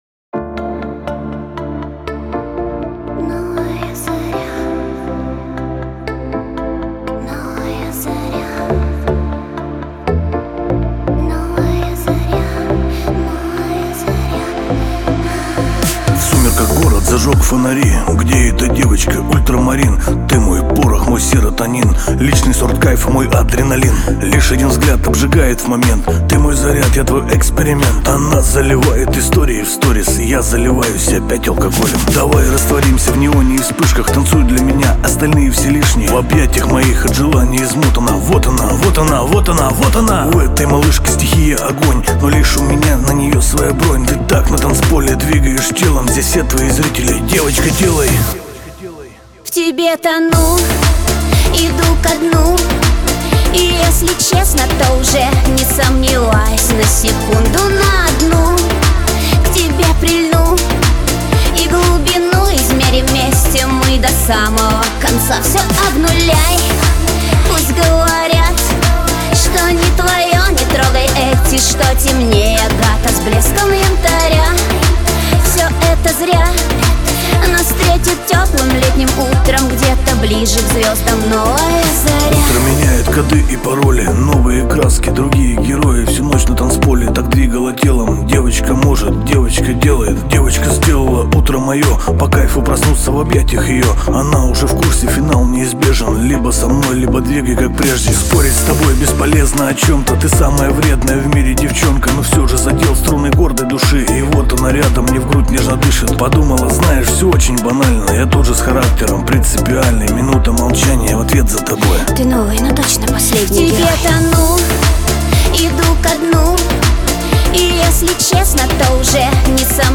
эстрада , диско
pop